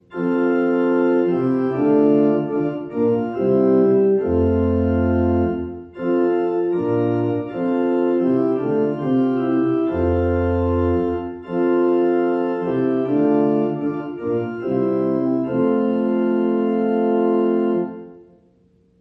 TLH Matins/Vespers Kyrie (F Major)